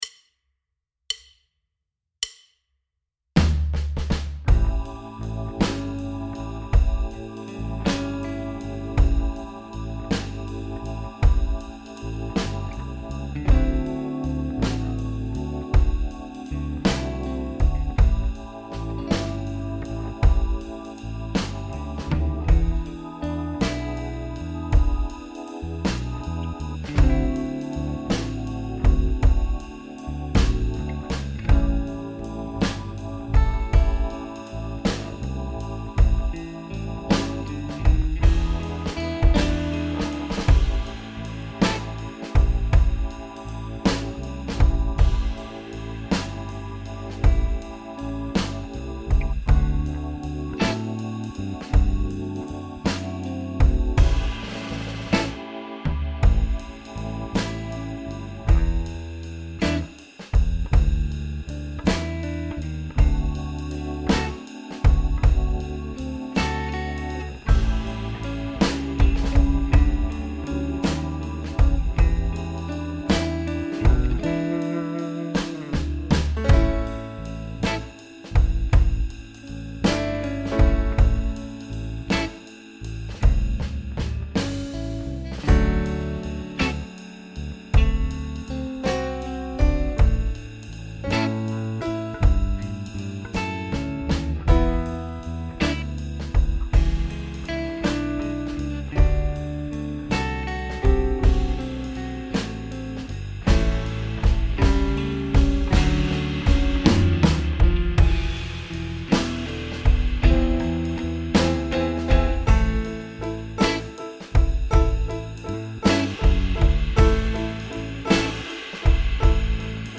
1: It All Starts With The Blues (Key of A)
Lesson 1